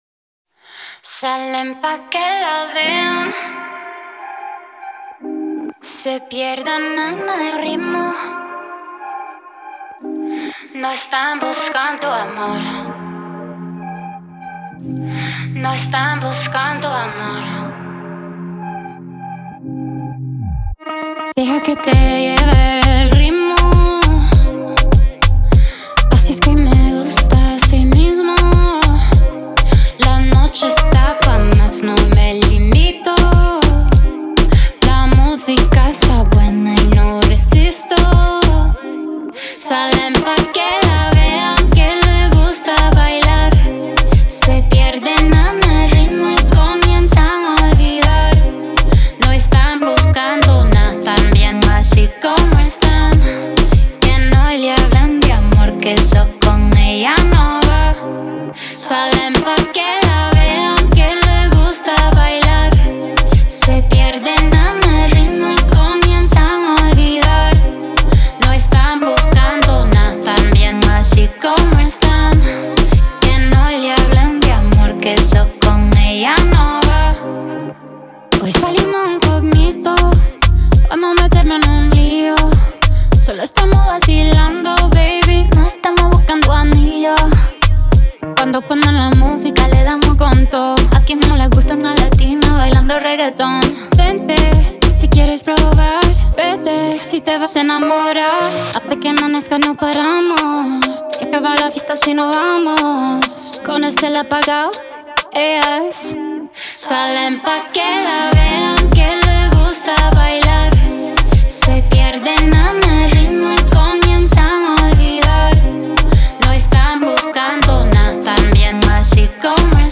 это яркая и мелодичная поп-песня
наполненная энергией и романтическим настроением.